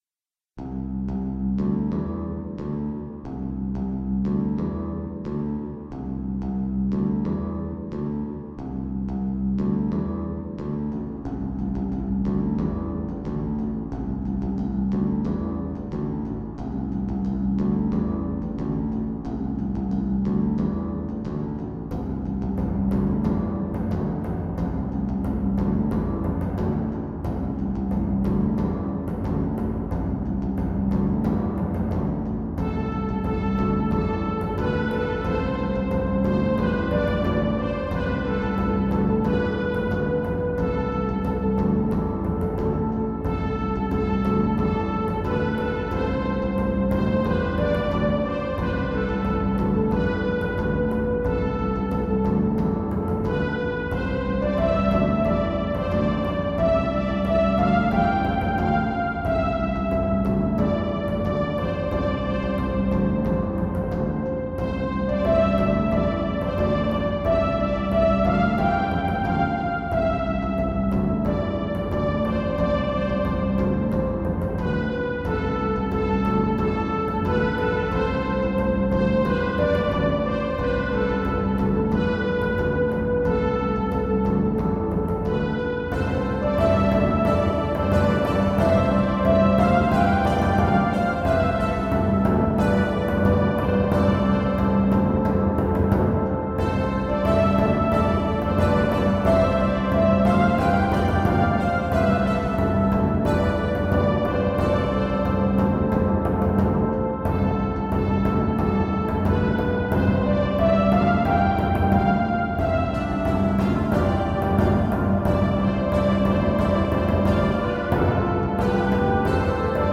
Rhythmic piece that makes me want to go fight a war, for bass, drum and synthetic horns.
warfare.mp3